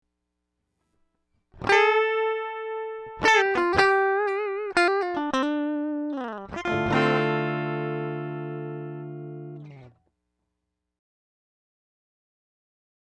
Чтобы установить базовый уровень, ниже приведена неусиленная запись гитары, просто записанная через наш интерфейс без применения дополнительных эффектов.